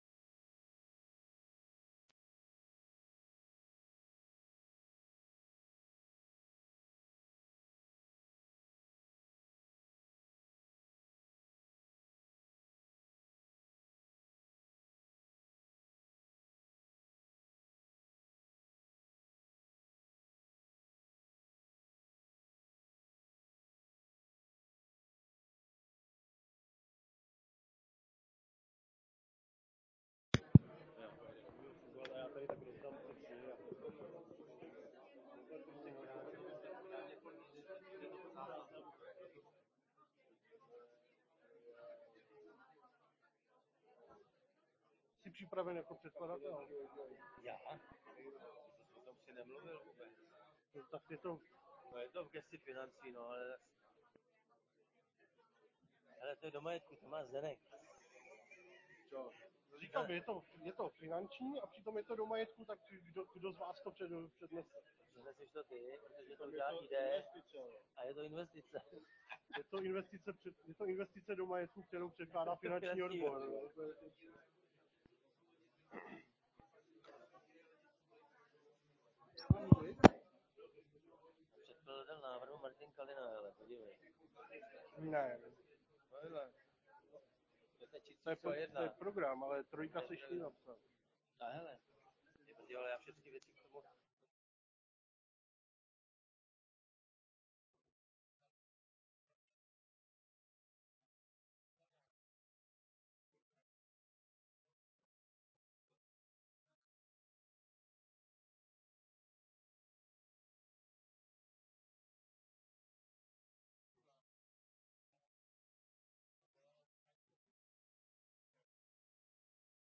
Město Mariánské Lázně: 4. jednání zastupitelstva města Mariánské Lázně (18.3.2019) c41a2b86b257a05222d3fb94fe586e01 audio